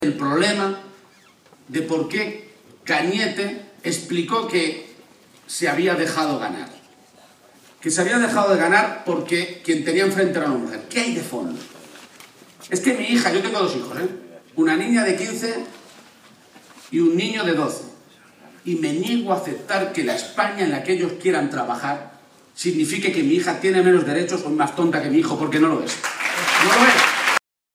“No hay que caer en la trampa, hay que parar la coartada del Partido Popular”, insistió, en transcurso del mitin de cierre de campaña que esta noche se celebró en Albacete.